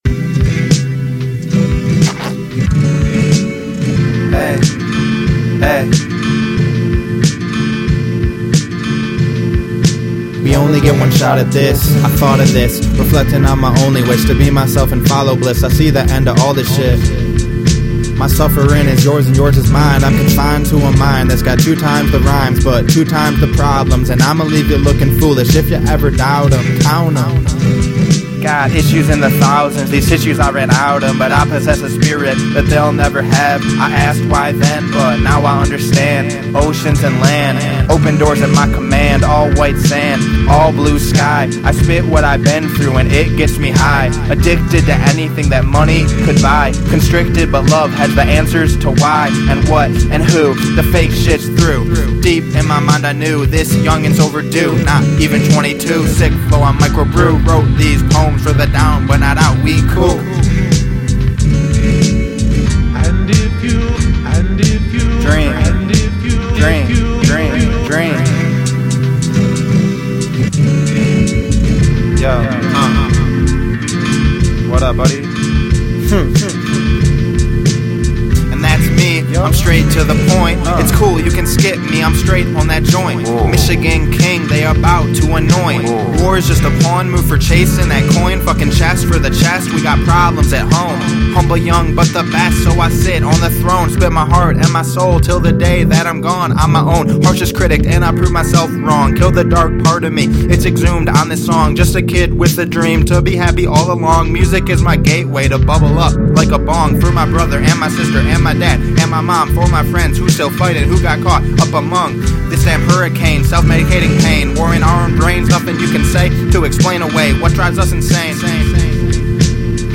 We Only Get One Shot At This: A Rap